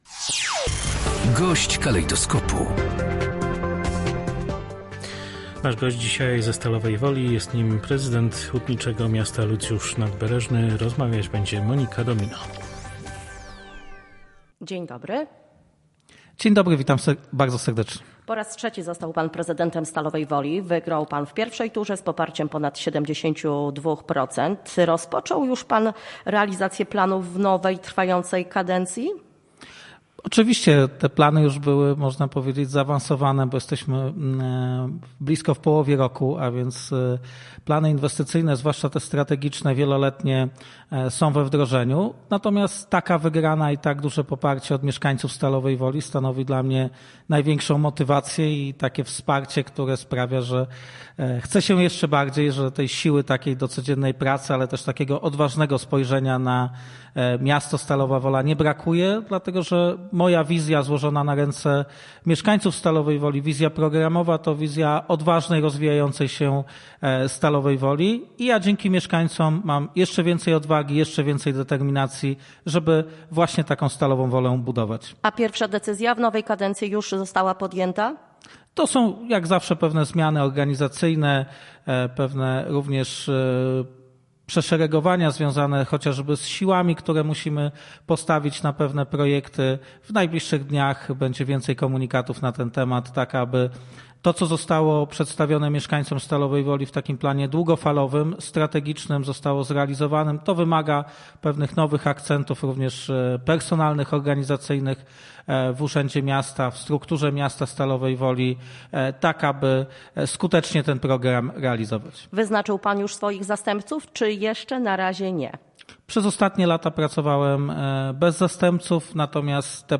GOŚĆ DNIA. Lucjusz Nadbereżny, prezydent Stalowej Woli
– poinformował włodarz miasta, gość Polskiego Radia Rzeszów.